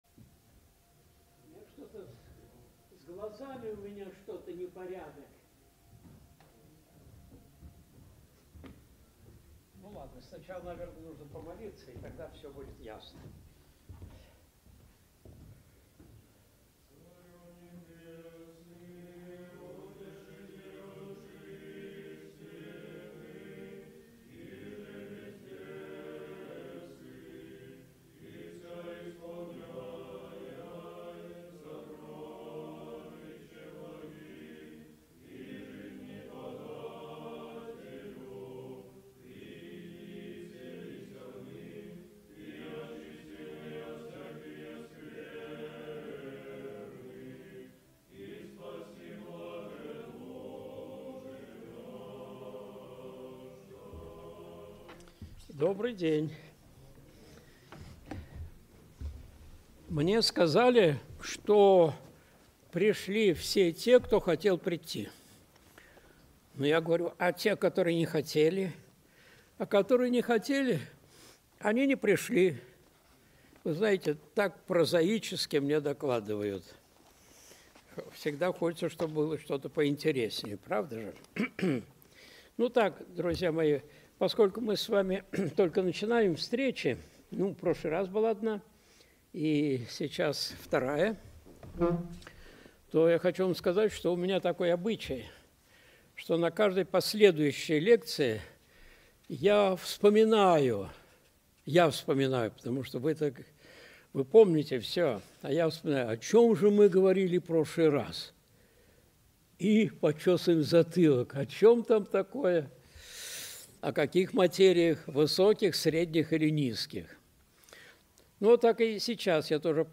Христианские истины, которых никогда не было в истории религии (Часть 1) (Прямая трансляция, курс по Апологетике в МДА, 31.01.2025)
Видеолекции протоиерея Алексея Осипова